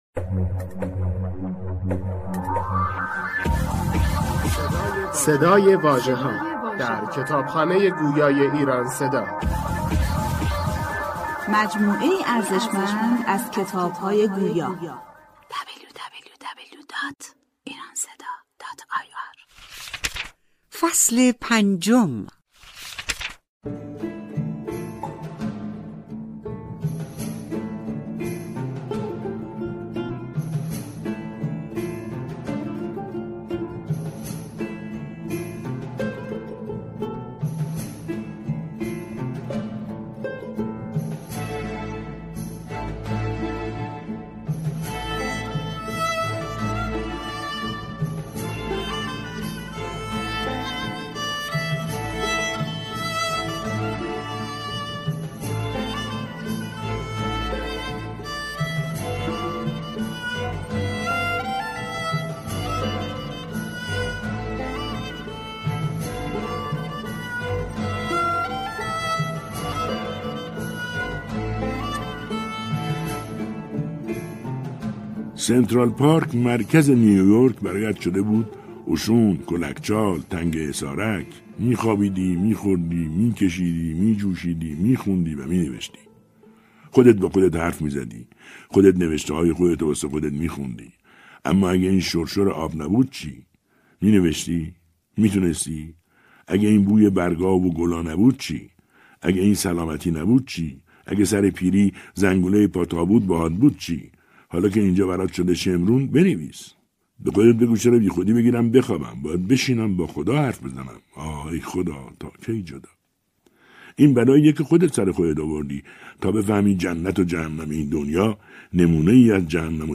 کتاب صوتی ده سال هوملسی امریکا نوشته سید محمود گلابدره ای فصل پنجم